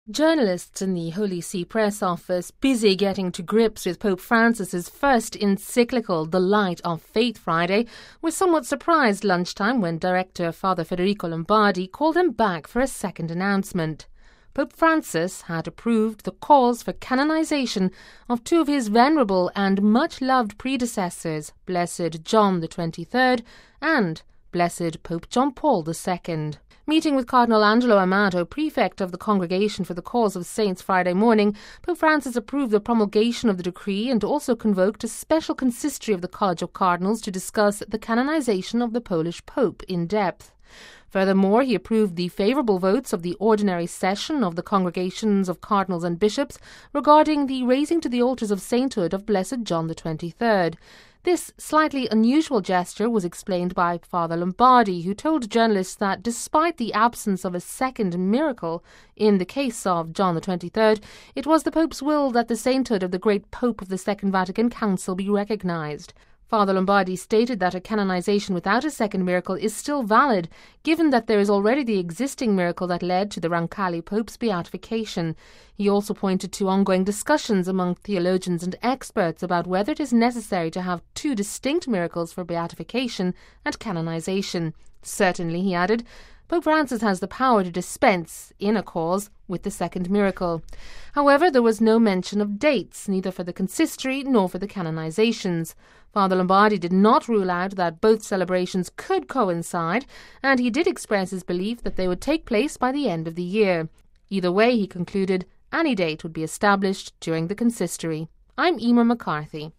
(Vatican Radio) Journalists in the Holy See Press Office busy getting to grips with Pope Francis’ first encyclical the Light of Faith